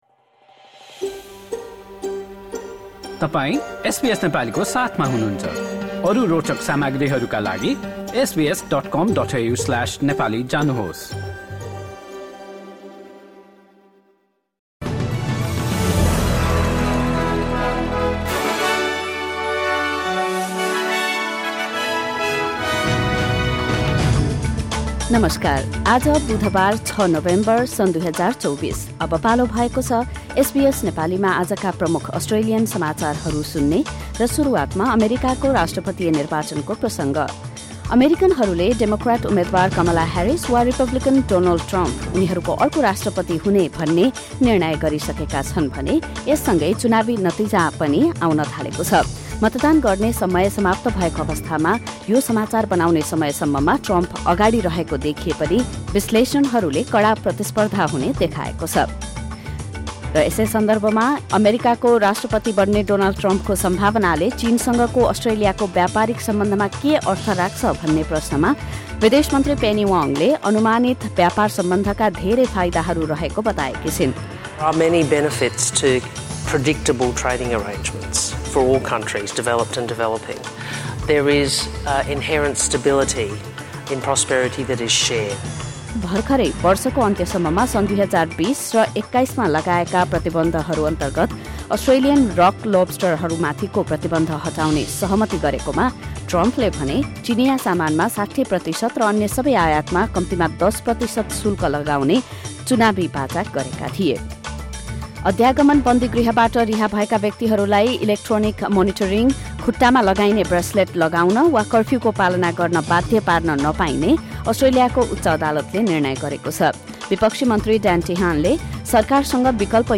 SBS Nepali Australian News Headlines: Wednesday, 6 November 2024